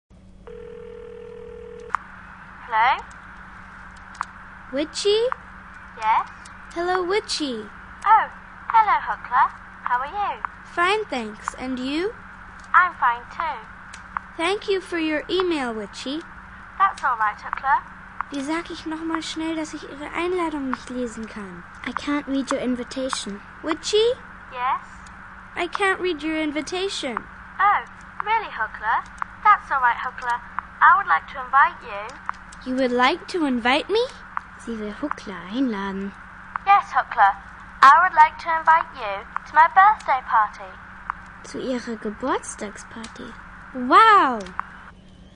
Ein spannendes Hörspiel-Abenteuer für Kinder im Vor- und Grundschulalter, bei dem sie spielerisch ca. 250 englische Wörter sprechen und anwenden lernen.
kindgerechte Texte, von Kindern gesprochen und gesungen